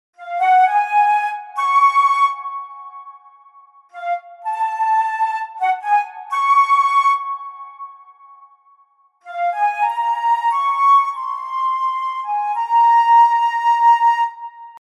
１時間軽く今回の方法を弾いてみて見つけた３つのメロディの卵たちです。